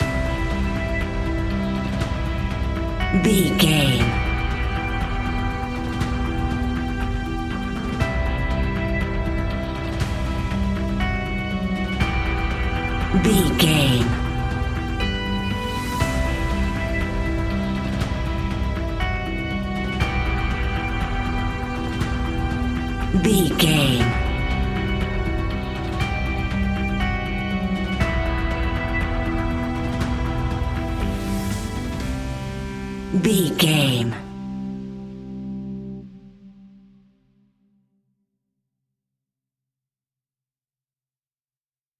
royalty free music
Aeolian/Minor
anxious
dramatic
intense
epic
synthesiser
drums
strings
suspenseful
creepy
horror music